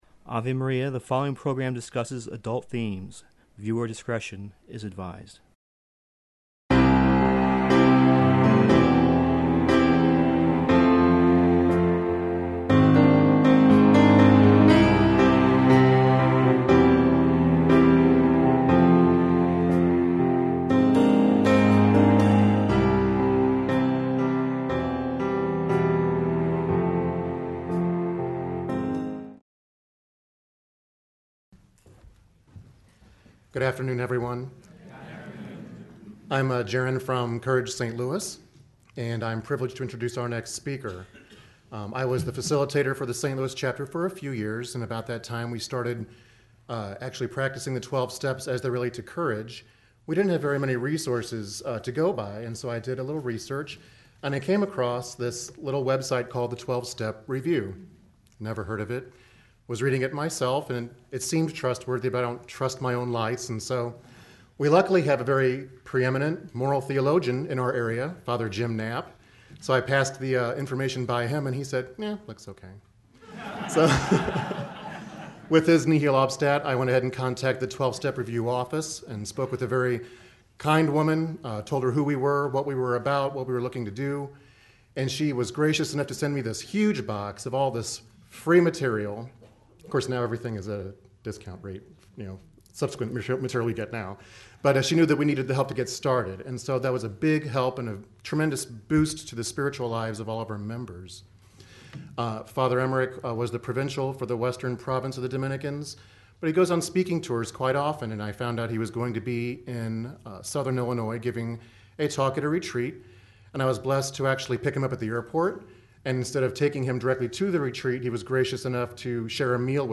2013 International Courage Conference in Mundelein, IL
talk